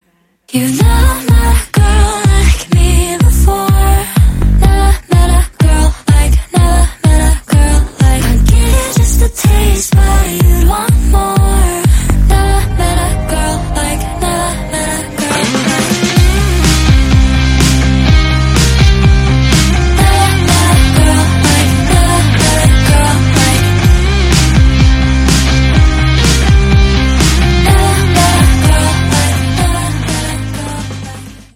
Громкие рингтоны
Танцевальные рингтоны